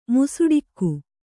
♪ musuḍikku